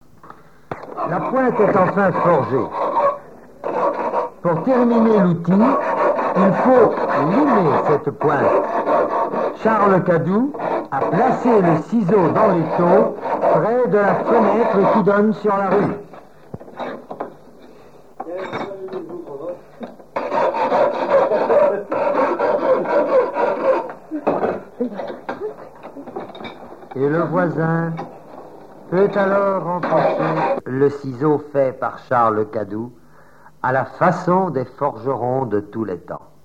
forgeron, forge
Île-d'Yeu (L')
Catégorie Témoignage